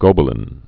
(gōbə-lĭn, gŏbə-)